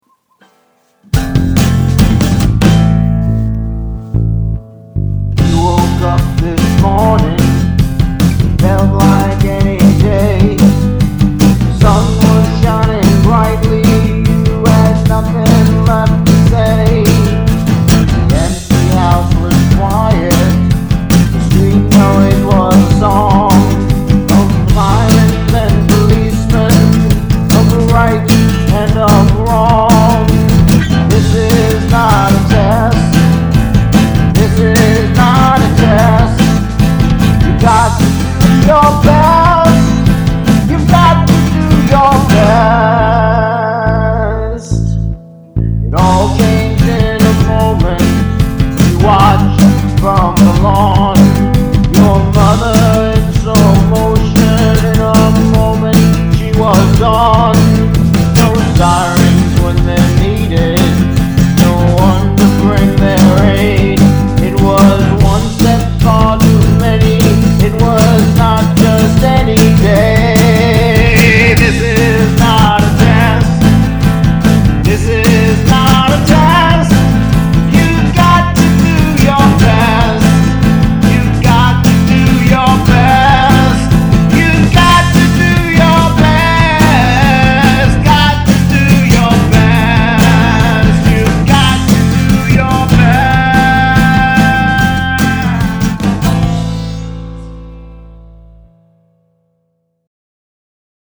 I hate the autotune.